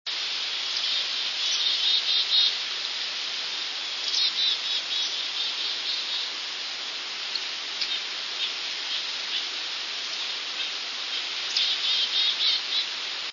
Kouchibouguac, New Brunswick, Canada (9/3/00), (52kb) family of Chickadees "dee-dee-dee" calls with Nuthatch, in mature pines at dusk
chickadee_black-capped_710.wav